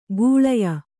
♪ gūḷaya